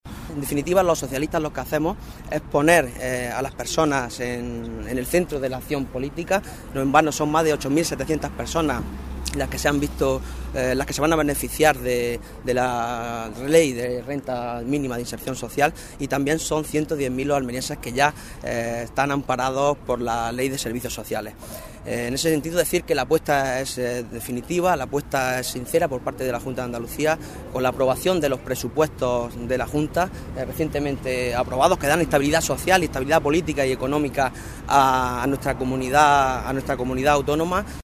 Acto informativo sobre la campaña Conquistando derechos, en el que ha participado la secretaria de Política Municipal del PSOE-A, María Jesús Serrano